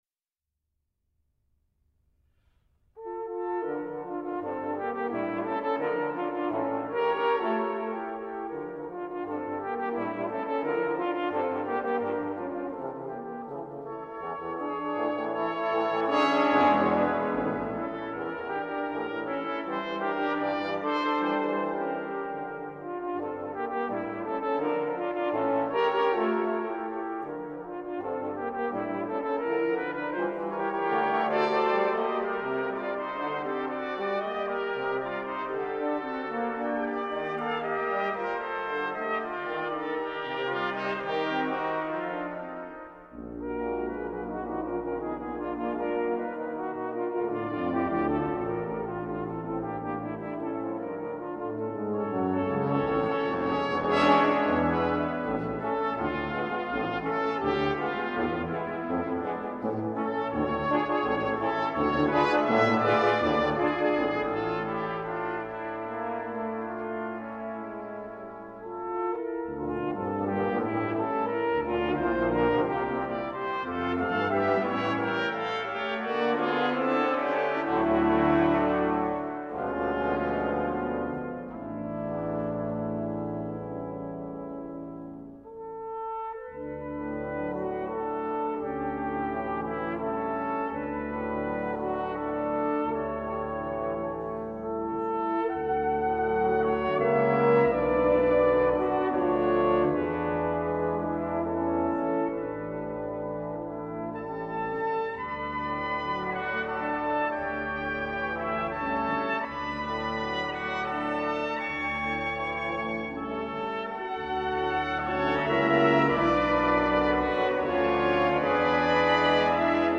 Instrument: brass tentet (score and parts)